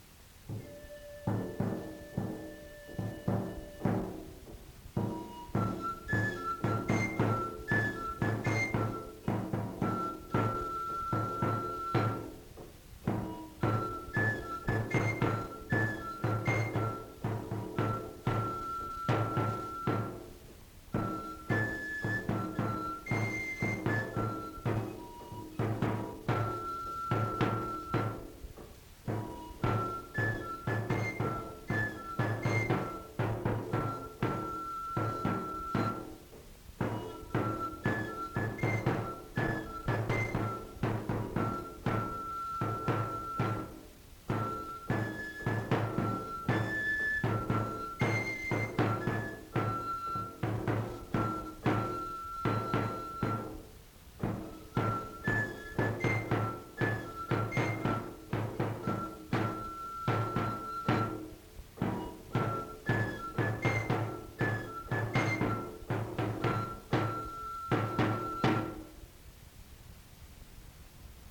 Instrumental. Flûte d'écorce
Aire culturelle : Cabardès
Genre : morceau instrumental
Instrument de musique : flûte ; tambour